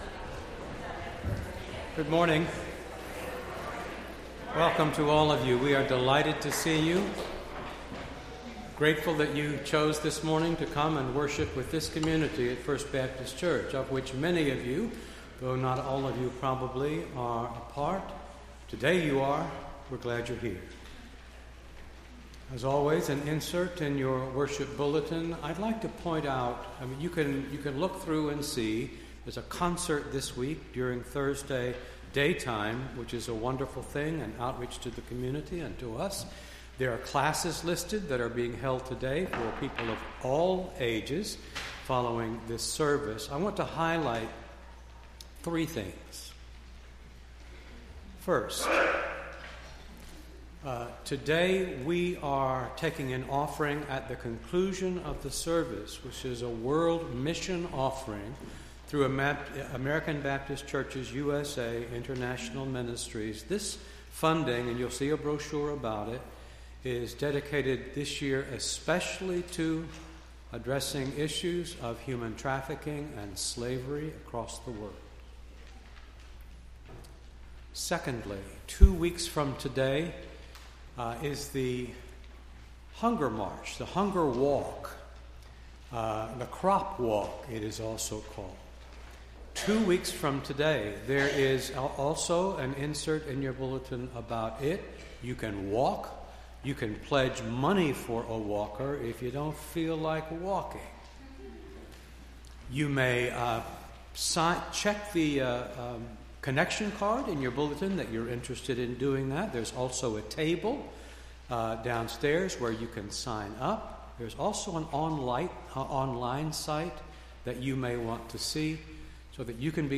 Entire October 1st Service